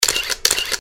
быстрые
щелчки
Звук затвора на старом поколении камер Никон F4